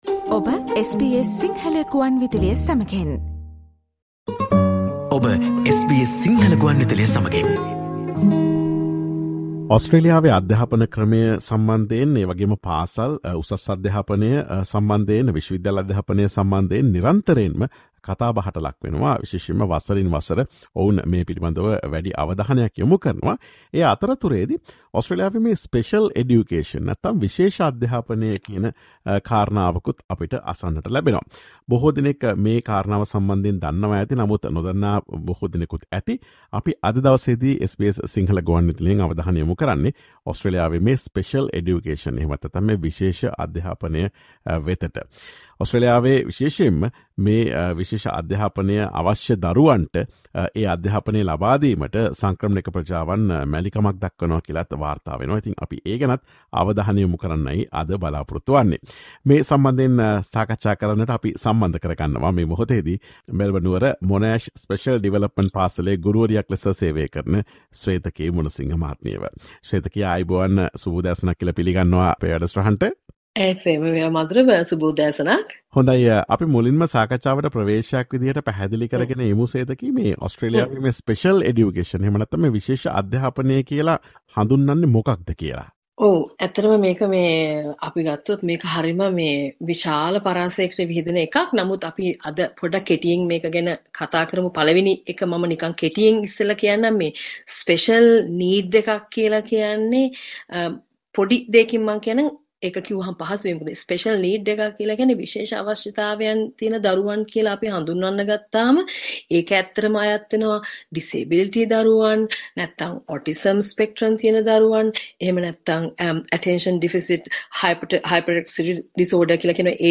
Listen to SBS Sinhala Radio's discussion on why many migrant communities are reluctant to send their children with special needs to special development schools in Australia.